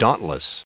added pronounciation and merriam webster audio
1143_dauntless.ogg